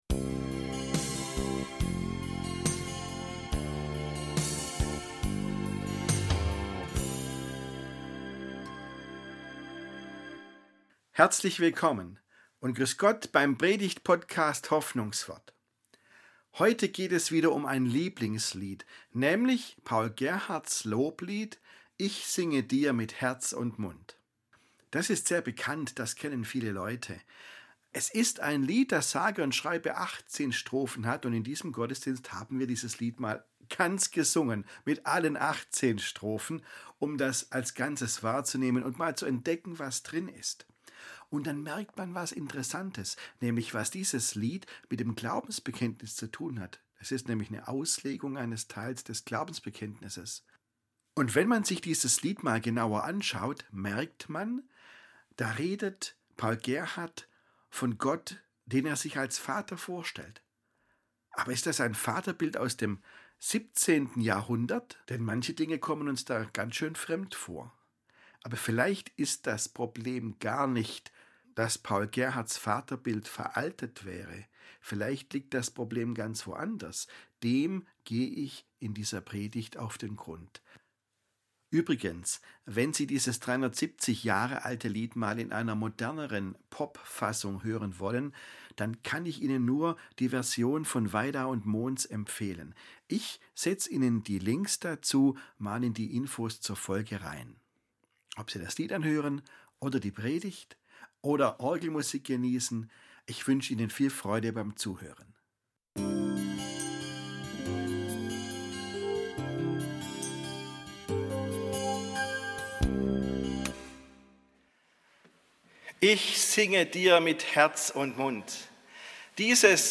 Paul Gerhards Loblied ist sehr bekannt. In dieser Predigt erfahren Sie, was es mit dem Glaubensbekenntnis zu tun hat, welchen Weg der Liederdichter in den 18 Strophen des Liedes geht und wie sich Paul Gerhardt Gott als Vater vorstellt.